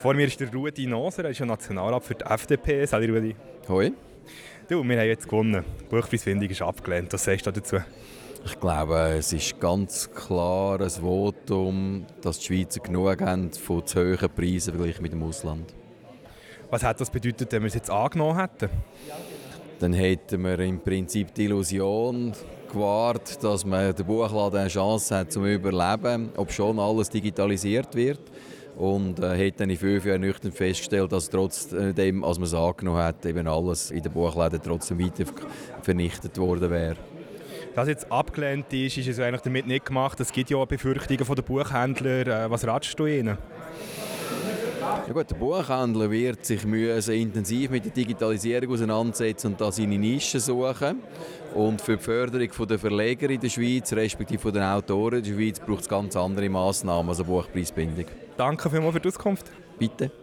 Abstimmungsparty - Ruedi Noser